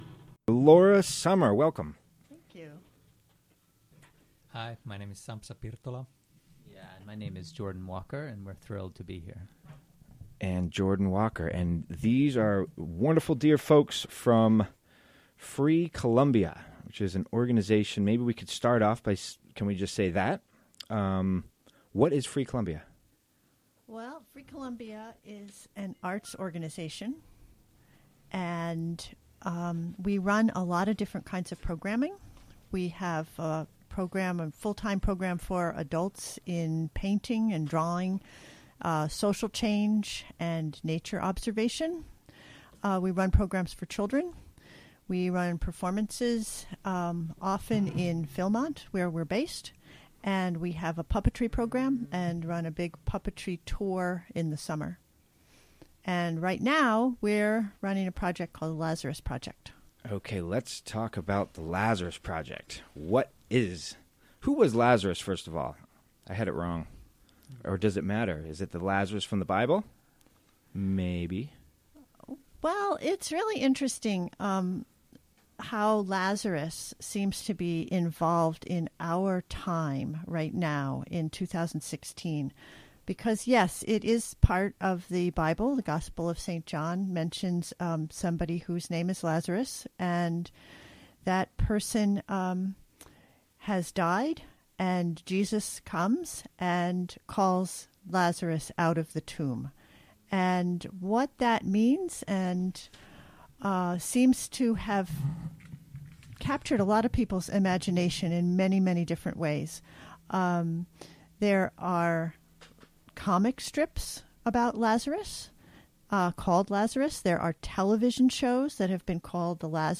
Interview from the WGXC Afternoon Show May 2.